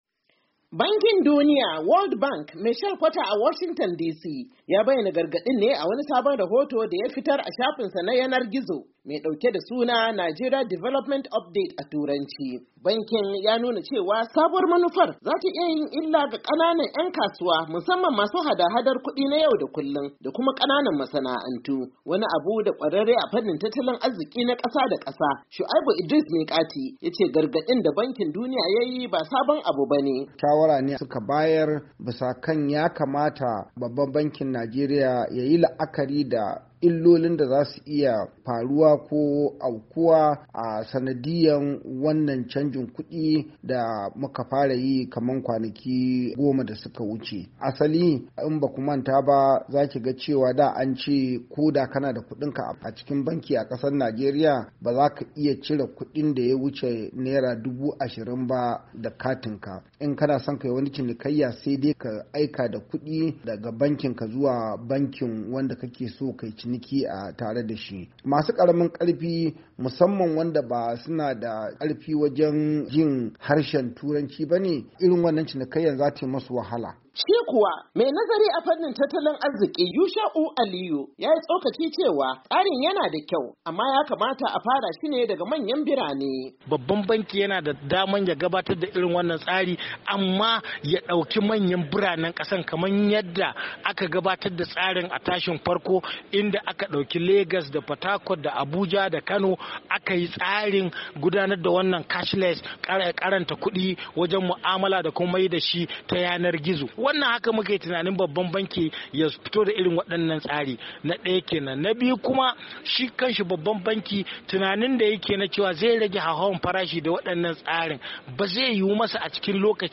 CBN CASHLESS POLICY WILL AFFEC THE POOR AND SME's---REPORT.mp3